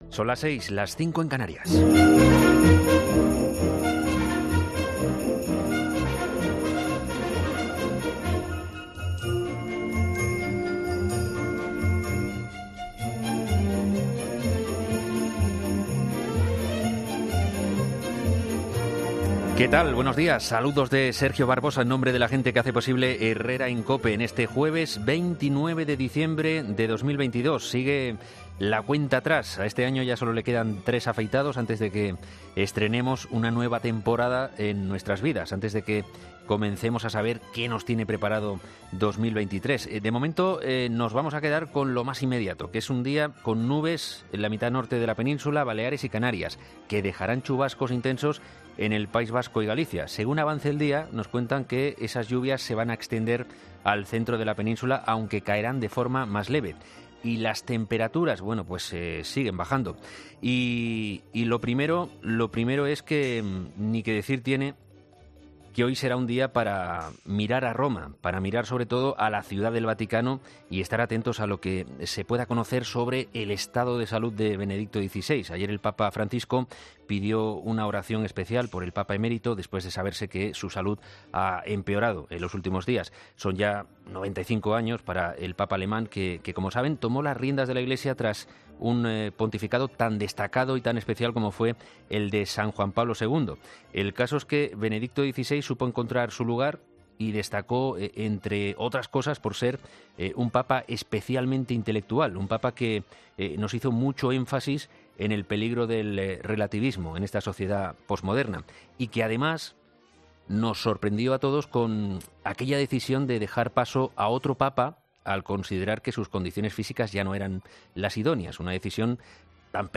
subdirector y presentador de ' Herrera en COPE ', ha comenzado el programa de este viernes analizando las principales claves de la jornada